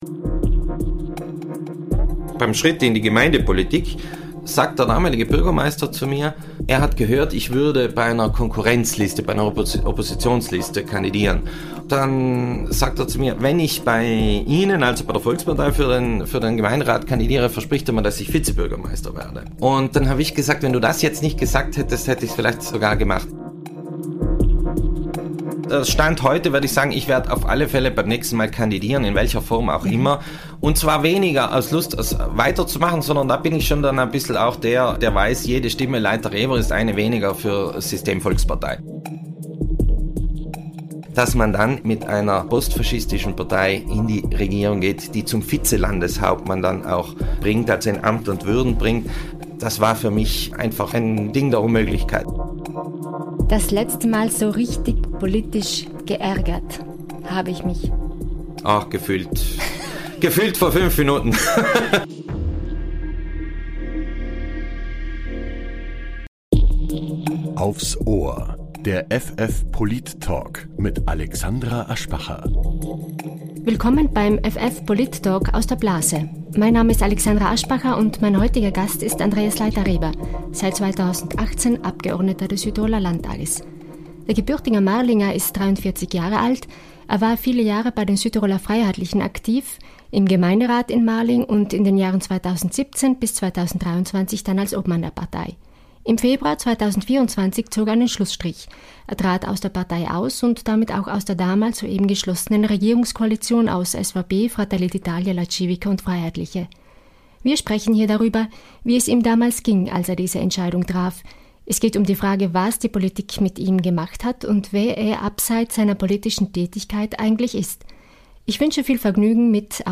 Der Politik-Talk
Gast in Folge 15 ist Andreas Leiter Reber, Landtagsabgeordneter der Freien Fraktion und von 2017 bis 2023 Parteiobmann der Südtiroler Freiheitlichen.